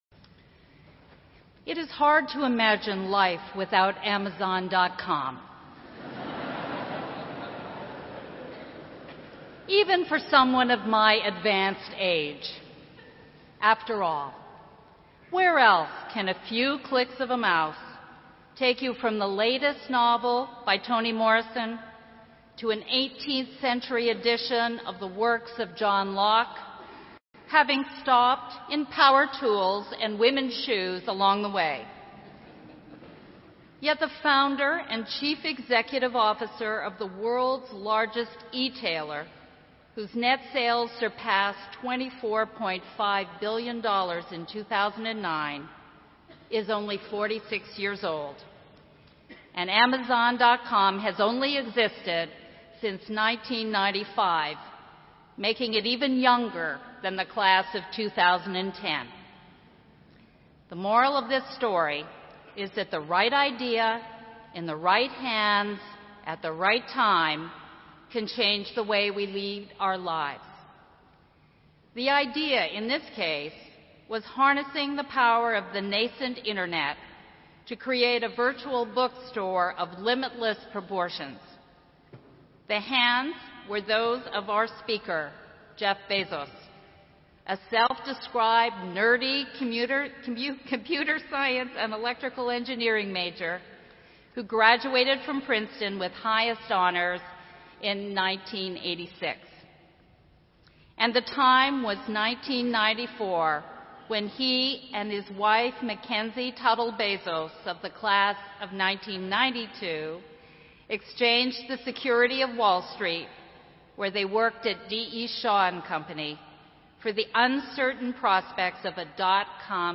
Amazon CEO Jeff Bezos 2010 graduation Speech at Princeton | Enhanced Education Group
Amazon-founder-and-CEO-Jeff-Bezos-delivers-graduation-speech-at-Princeton-University.mp3